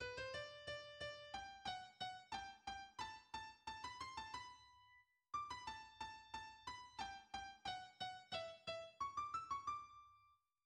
en sol majeur
Genre Concerto pour piano
Introduction de l'Allegretto :